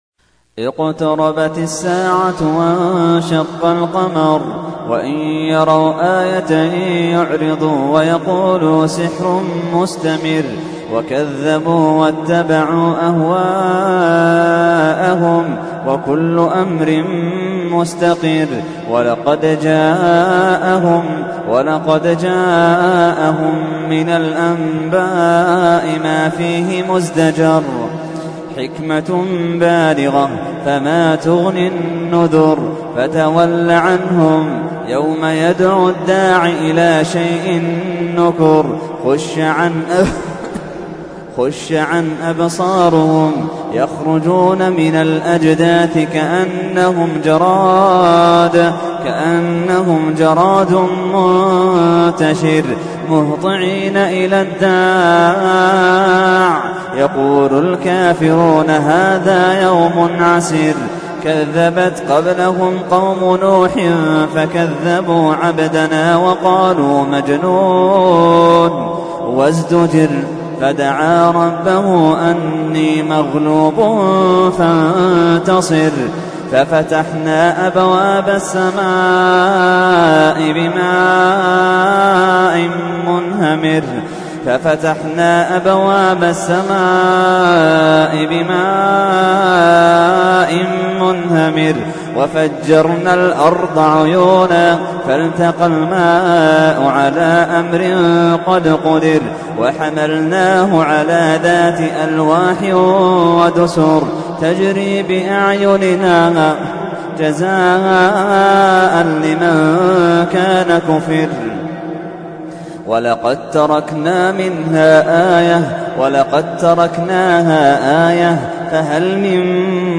تحميل : 54. سورة القمر / القارئ محمد اللحيدان / القرآن الكريم / موقع يا حسين